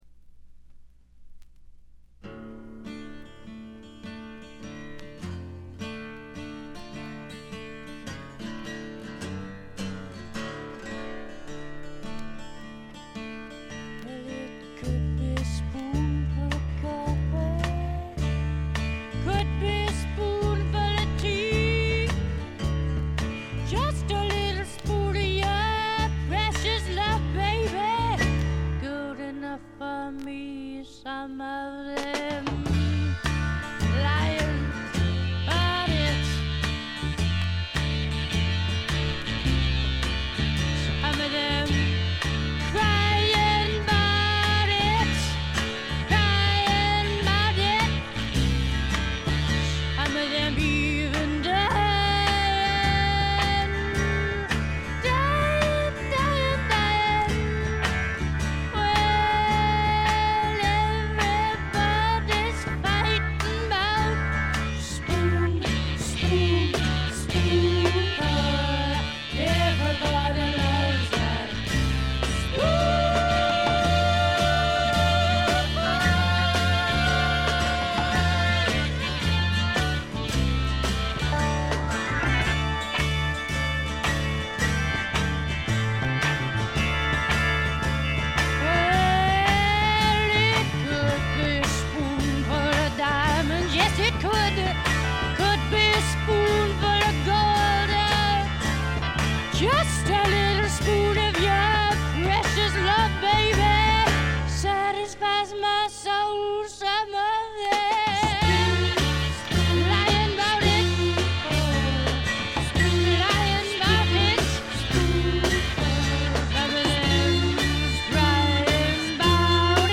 わずかなノイズ感のみ。
歪みまくったギターやオルガンの音が渦巻く中で何かに憑かれたようなヴォーカルを披露しており
試聴曲は現品からの取り込み音源です。
Piano, Organ